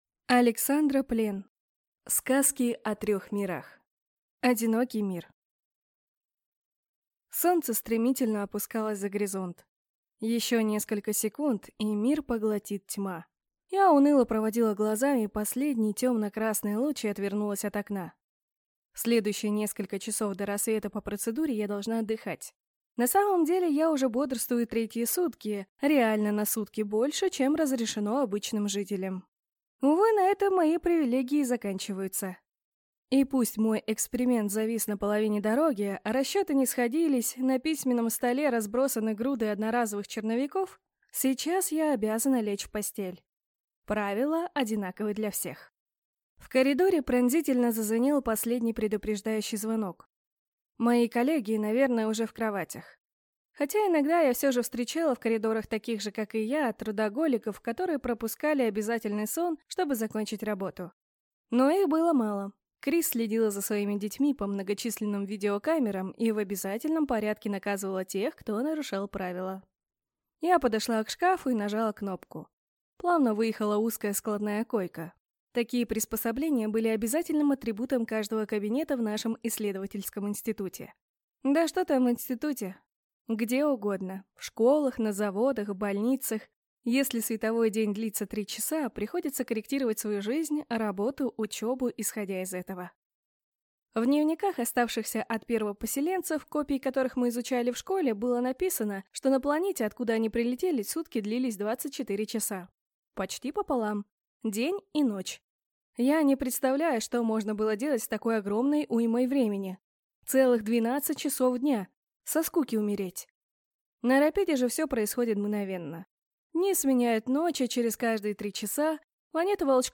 Аудиокнига Сказки о трех мирах | Библиотека аудиокниг